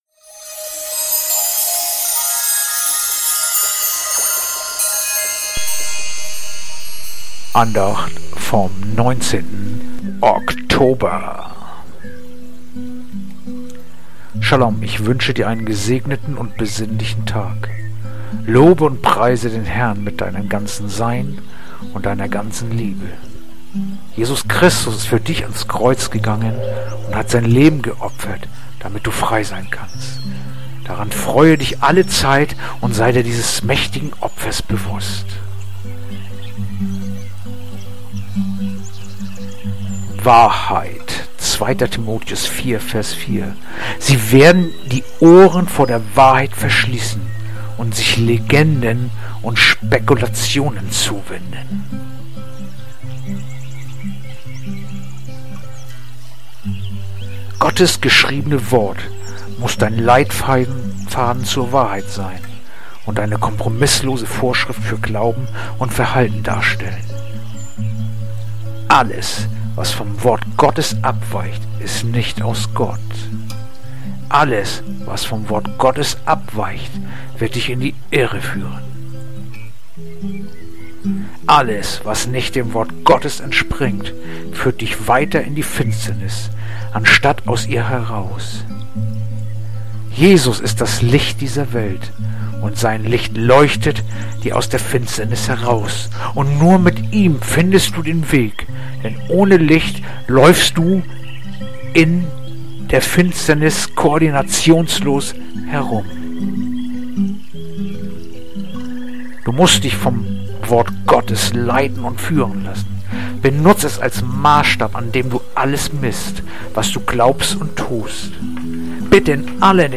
Andacht-vom-19-Oktober-2-Timotheus-4-4.mp3